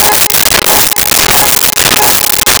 Car Alarm 01
Car Alarm 01.wav